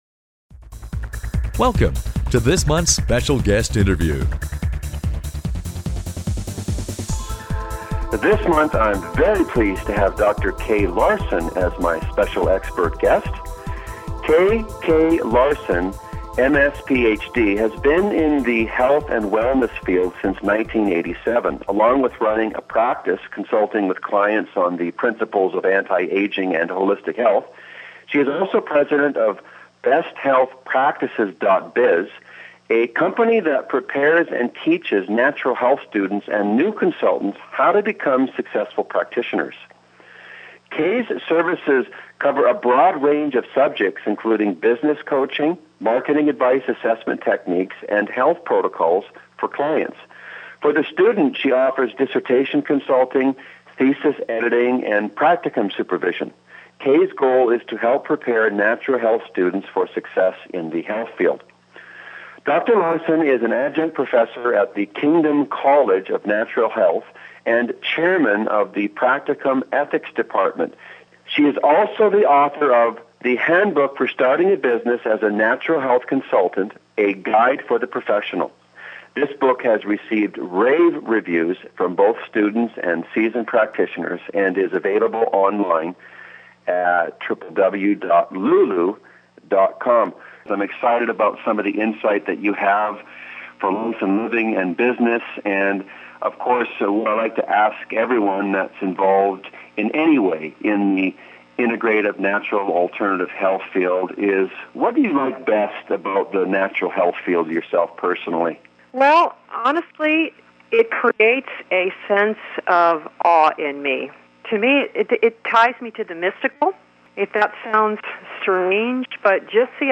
Special Guest Interview Volume 8 Number 11 V8N11c